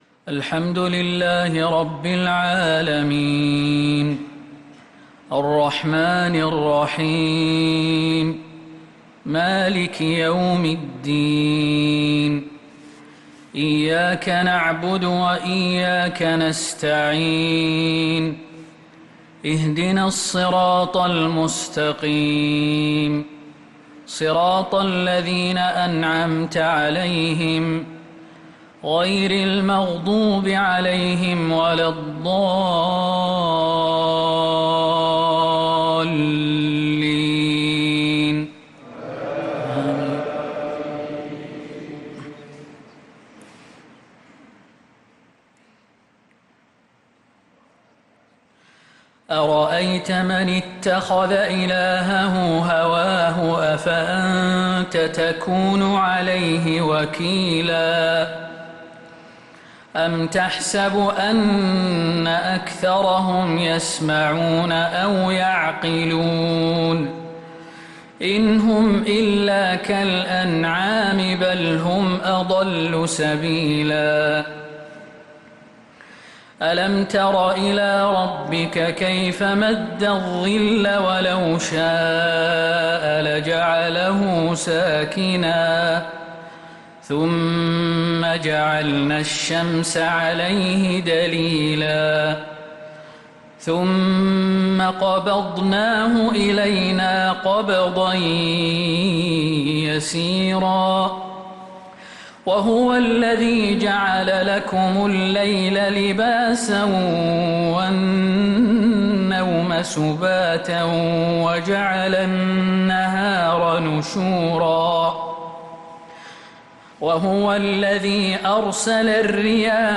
صلاة العشاء للقارئ خالد المهنا 10 ربيع الآخر 1446 هـ
تِلَاوَات الْحَرَمَيْن .